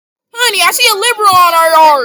Sound Effects
Long Reverb Wet Fart